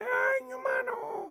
Audio / SFX / Characters / Voices / PigChef / PigChef_12.wav